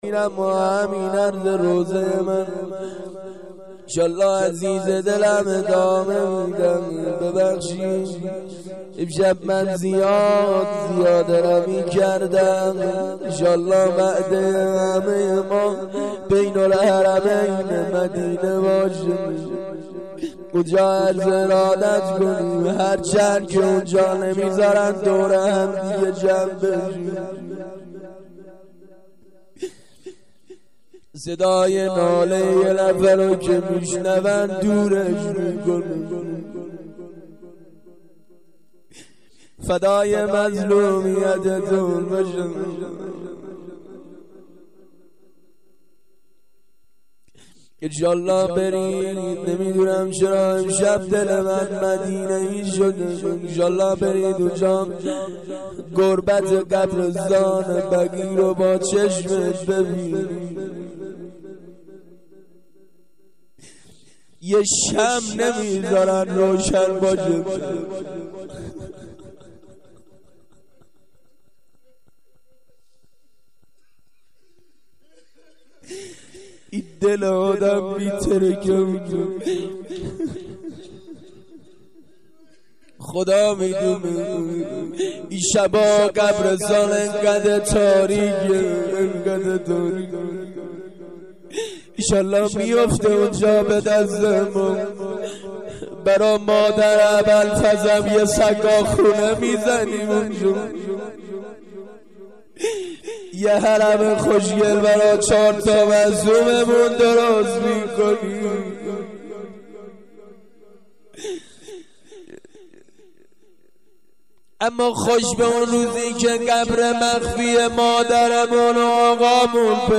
روضه حضرت رقیه(س)